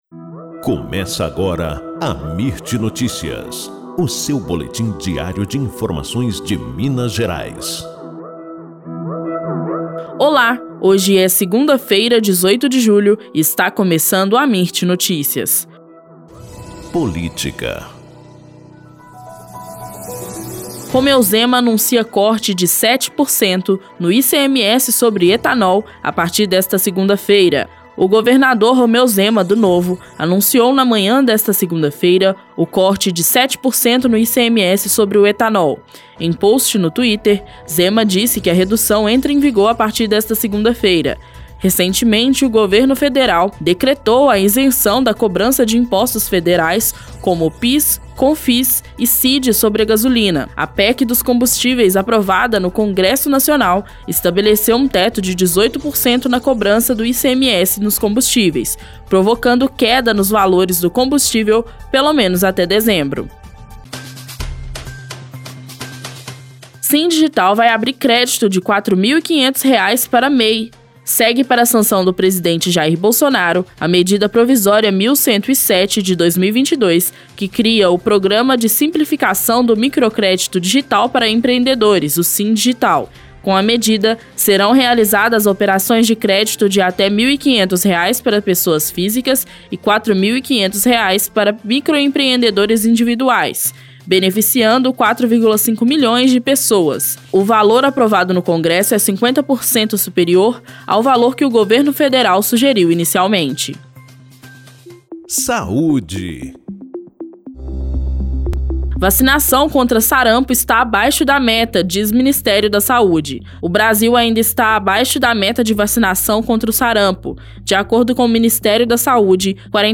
Boletim Amirt Notícias – 18 de julho